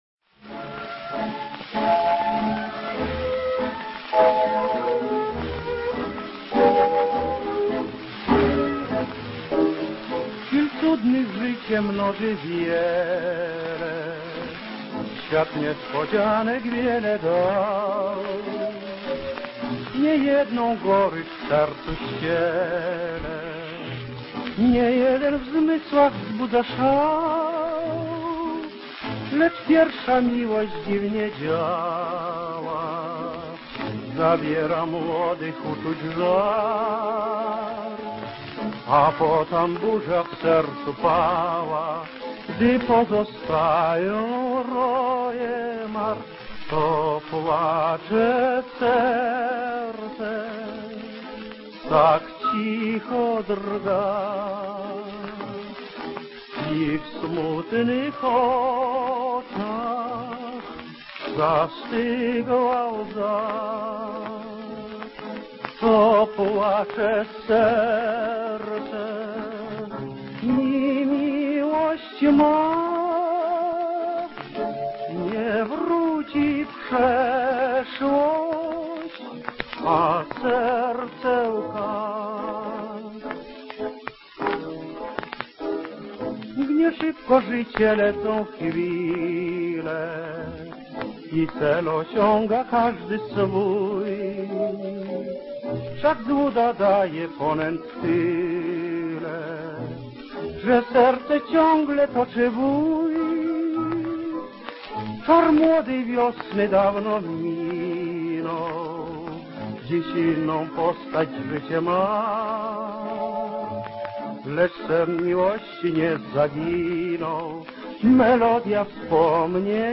Две грамзаписи, которые были сделаны в довоенной Польше в 1933 году (фактически одновременно):
Танго «To płacze serce» (этикетка слева) и «Tatjana»
Один и тот же оркестр (звукозаписывающей фирмы «Сирена-Рекорд» под управлением Хенрика Варса) и один и тот же исполнитель — Адам Астон (это, конечно, псевдонимы, а настоящие их фамилии — Варшавский и Левинсон).